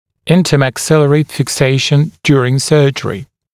[ˌɪntəmæk’sɪlərɪ fɪk’seɪʃn ‘djuərɪŋ ‘sɜːʤərɪ][ˌинтэмэк’силэри фик’сэйшн ‘дйуэрин ‘сё:джэри]межчелюстная фиксация по время хирургической операции